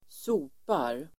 Uttal: [²s'o:par]